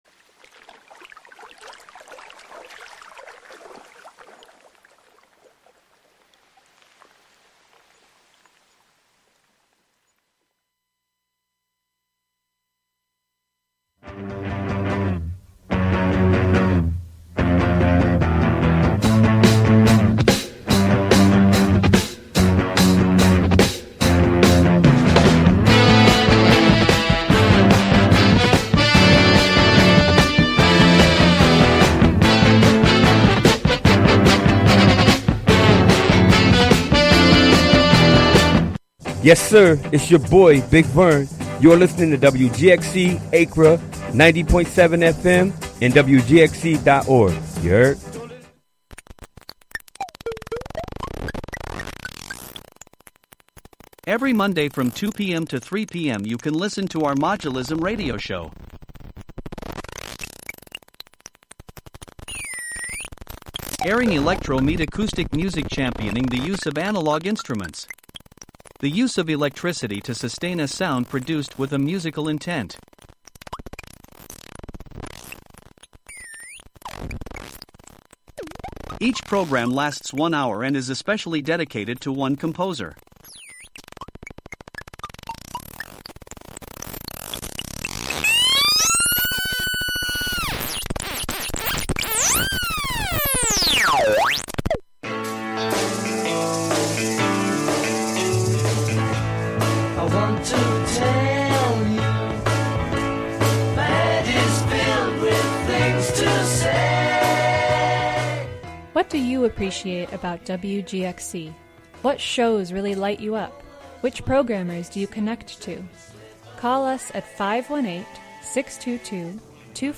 Hosted by various WGXC Volunteer Programmers.